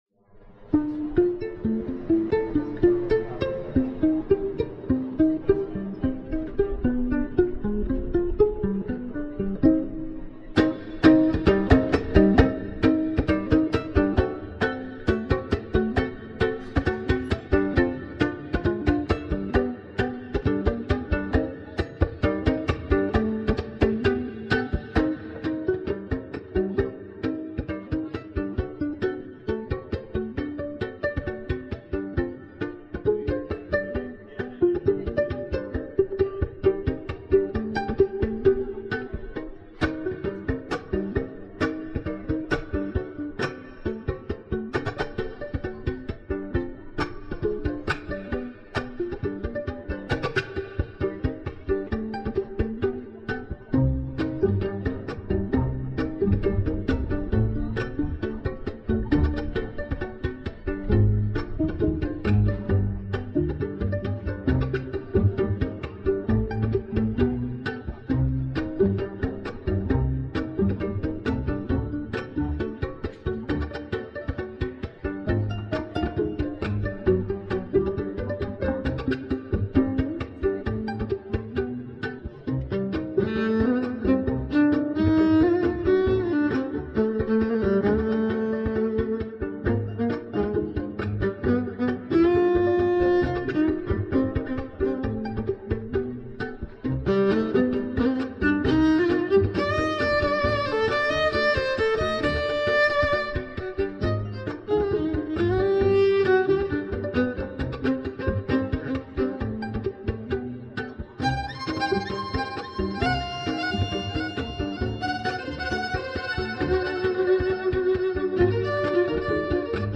Jazz Funk / Vocals